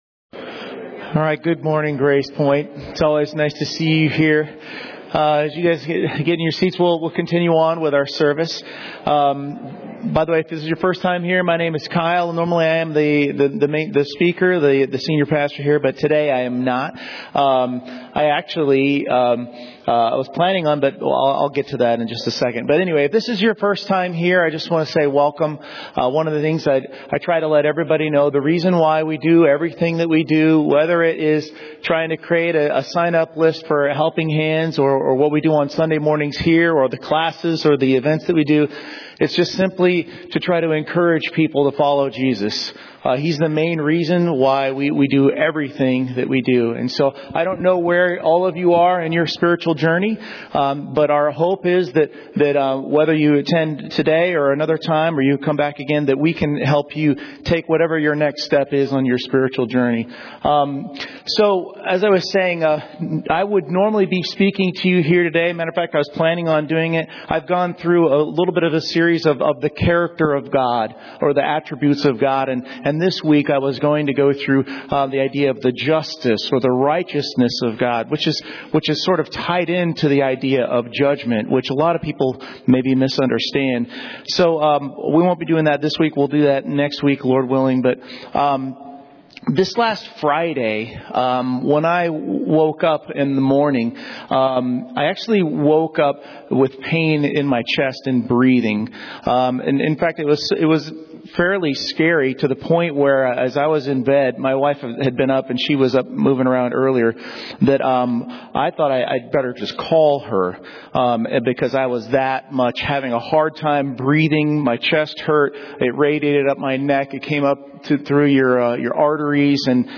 Series: 2024 Sermons